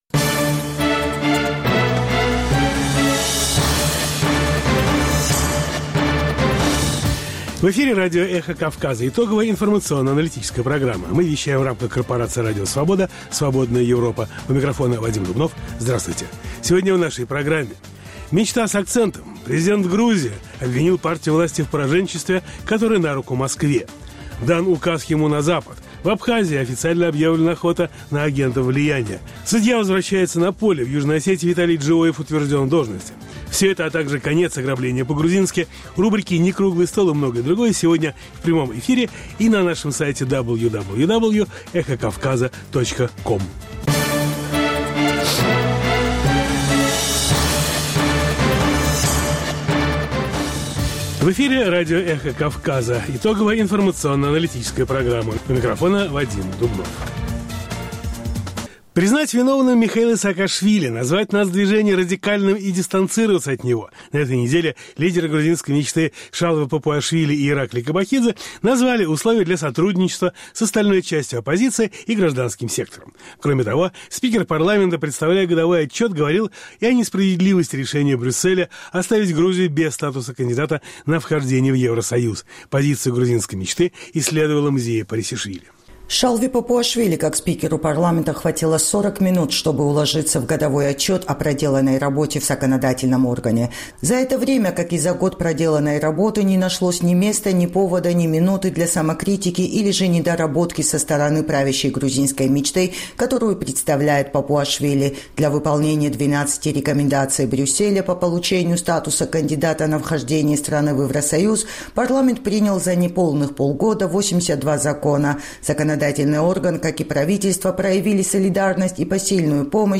Новости, репортажи с мест, интервью с политиками и экспертами, круглые столы, социальные темы, международная жизнь, обзоры прессы, история и культура.